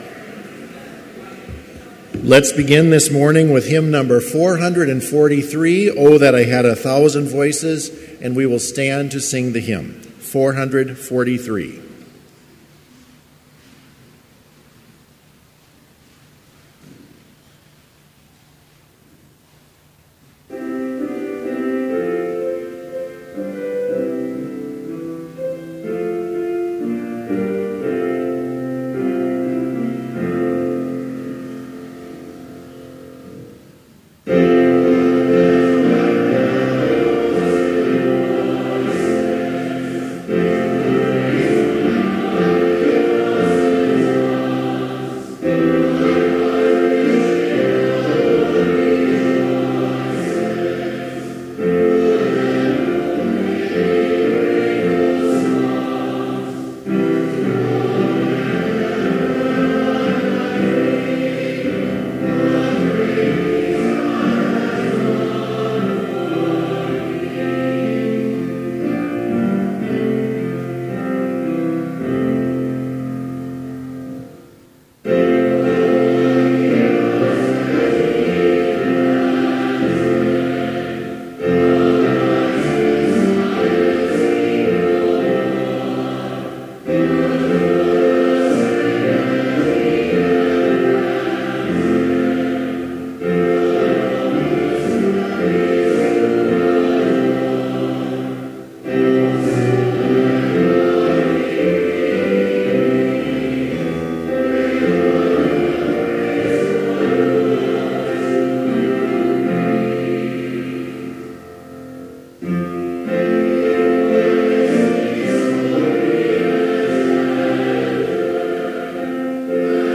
Complete service audio for Chapel - May 11, 2017